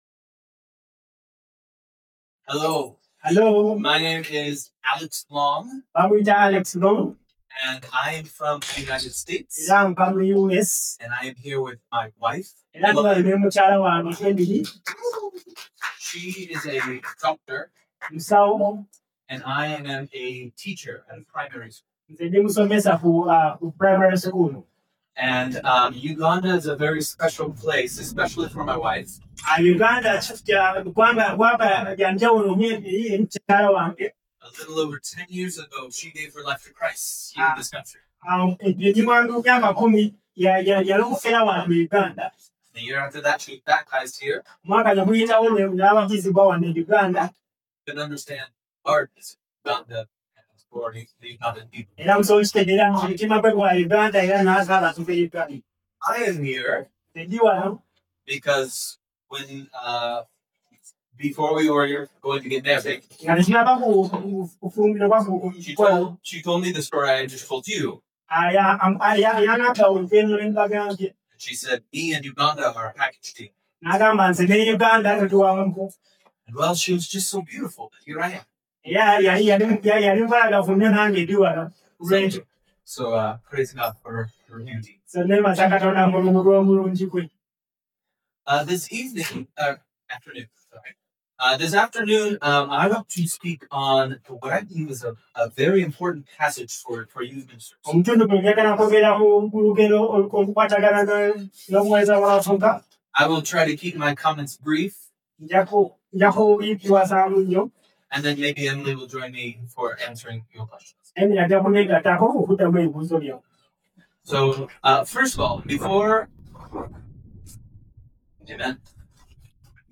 Sermons | Sozo Ministries